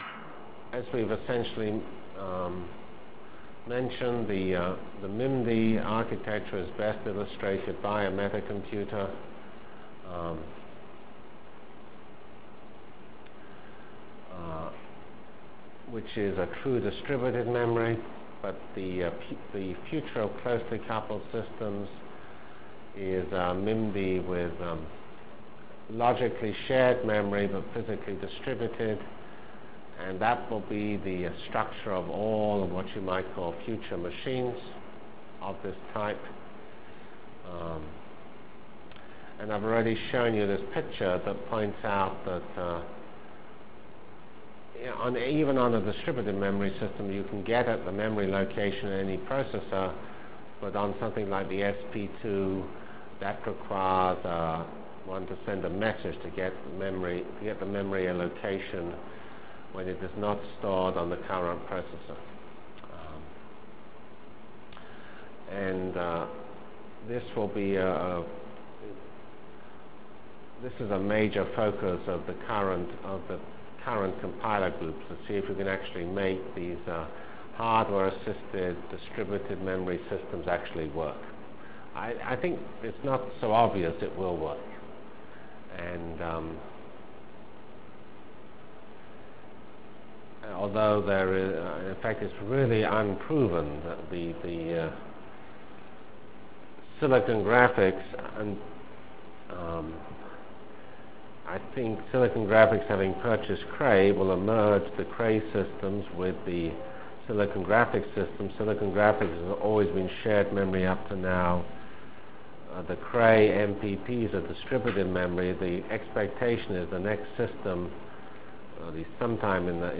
From CPS615-Lecture on Computer Architectures and Networks Delivered Lectures of CPS615 Basic Simulation Track for Computational Science -- 12 September 96. by Geoffrey C. Fox *